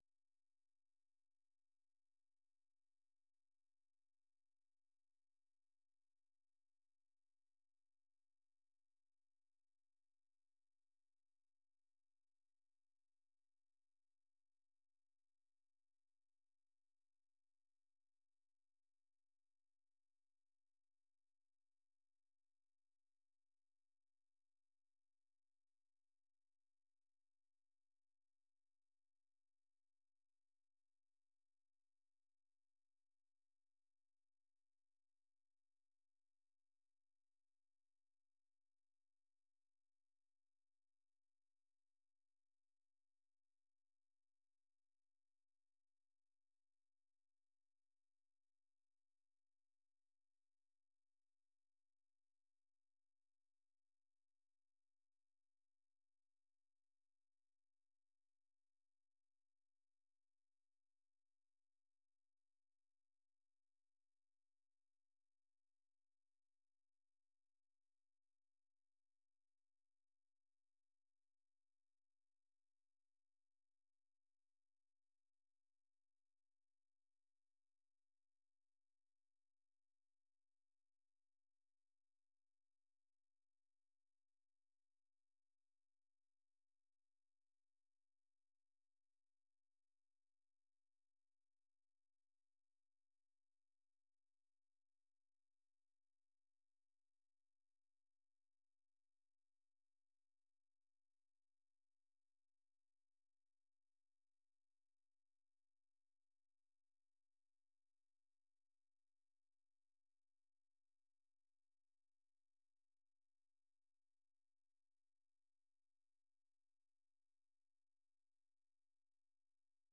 ቪኦኤ በየዕለቱ ከምሽቱ 3 ሰዓት በኢትዮጵያ አቆጣጠር ጀምሮ በአማርኛ፣ በአጭር ሞገድ 22፣ 25 እና 31 ሜትር ባንድ የ60 ደቂቃ ሥርጭቱ ዜና፣ አበይት ዜናዎች ትንታኔና ሌሎችም ወቅታዊ መረጃዎችን የያዙ ፕሮግራሞች ያስተላልፋል። ሐሙስ፡- ባሕልና ማኅበረሰብ፣ ሕይወት በቀበሌ፣ የተፈጥሮ አካባቢ፣ ሣይንስና ሕይወት